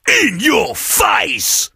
sam_ulti_vo_03.ogg